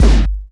GROWL KICK.wav